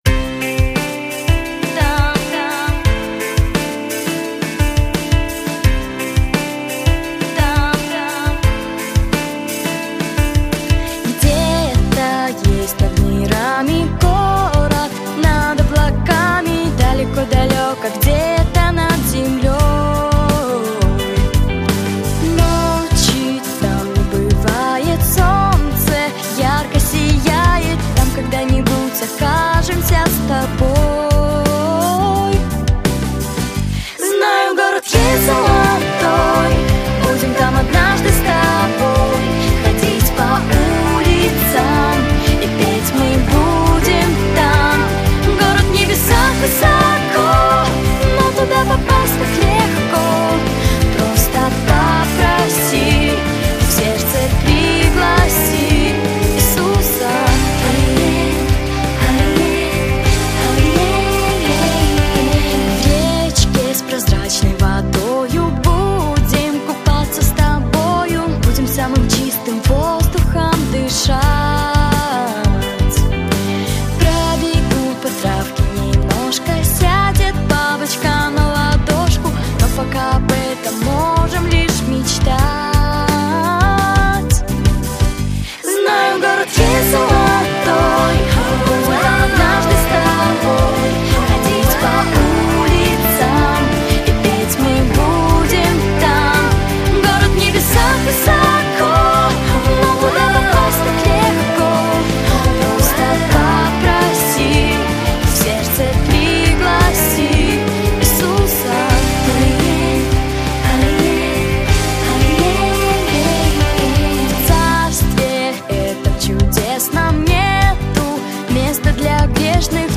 • Жанр: Детские песни
🎶 Детские песни / Песни на праздник / Христианские Песни ⛪